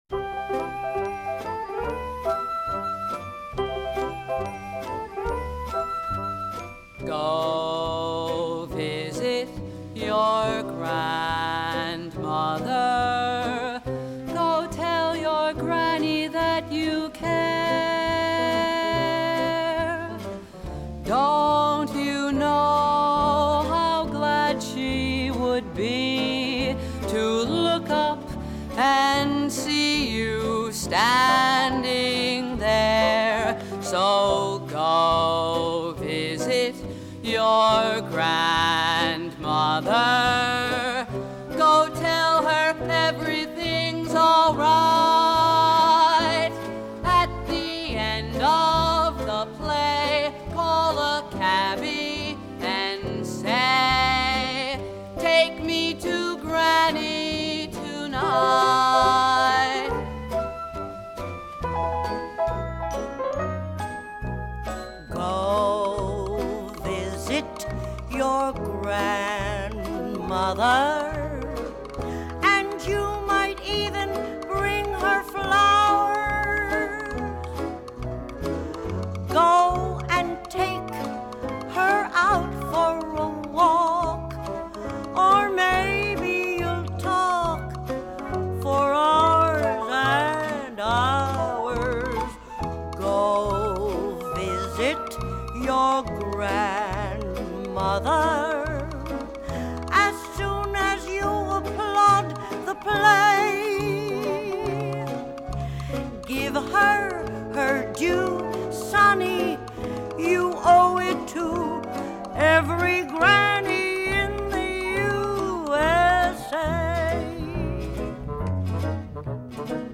1971  Genre: Musical   Artist